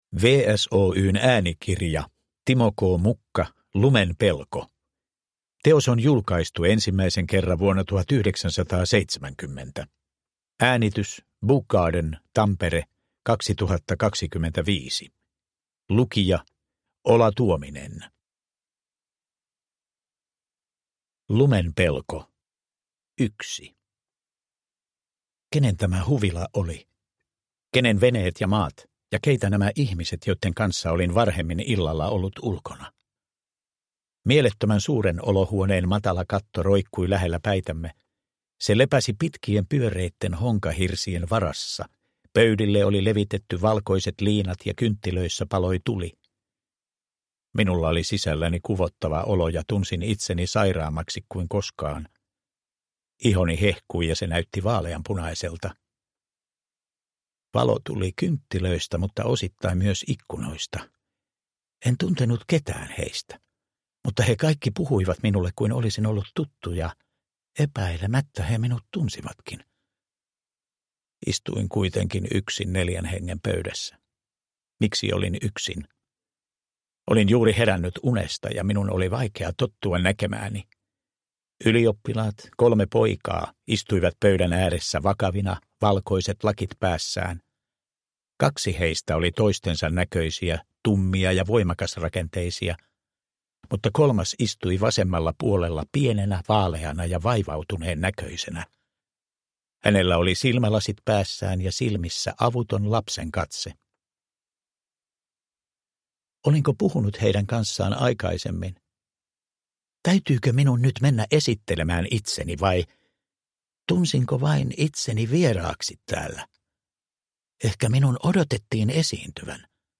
Lumen pelko – Ljudbok